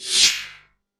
Fast Pass-By
A fast object passing by with Doppler shift, wind displacement, and quick fade
fast-pass-by.mp3